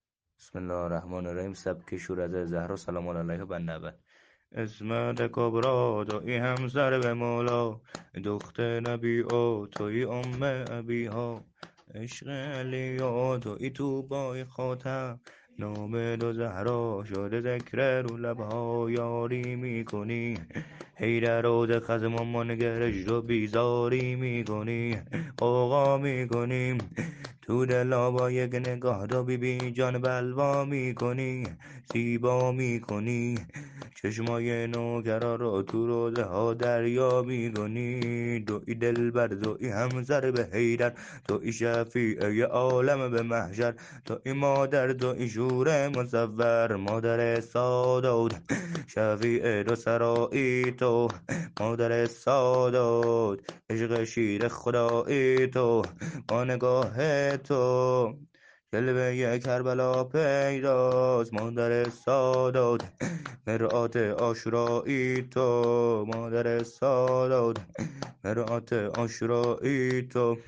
شور شهادت حضرت فاطمه زهرا (س) -(عصمتِ کبرا تویی همسر به مولا)